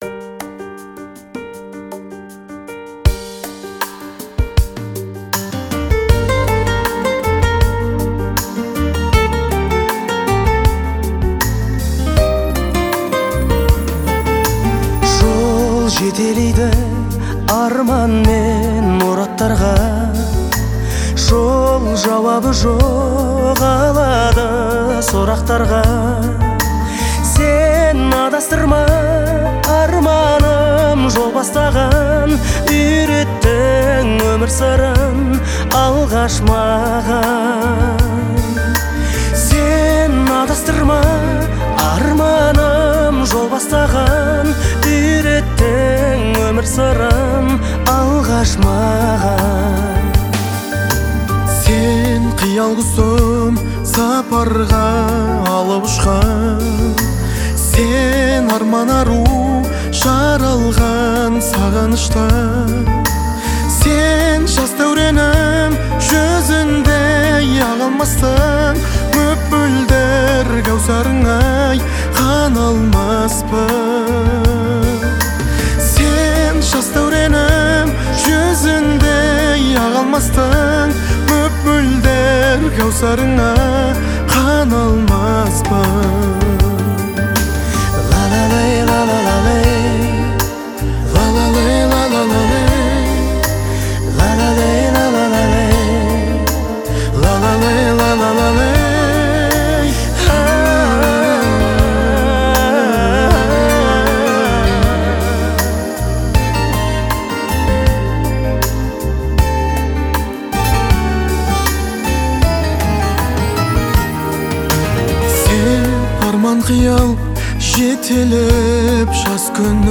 это яркий пример казахского поп-музыки